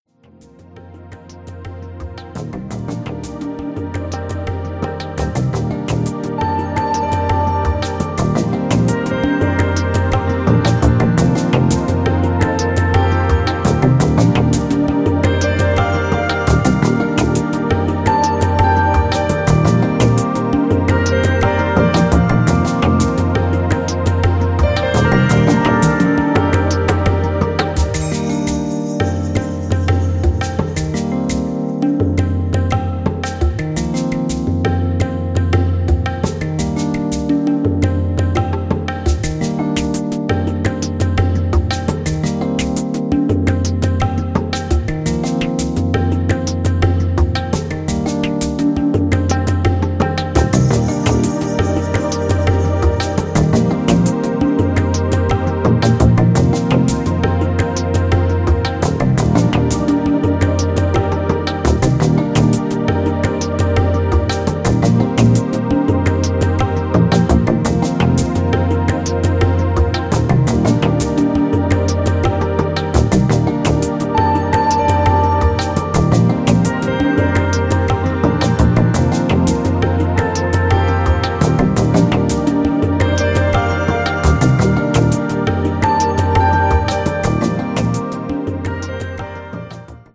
ドリーム・ハウスを彷彿とさせるキラー・バレアリック・ニューディスコ/ハウス！
ジャンル(スタイル) NU DISCO / BALEARIC HOUSE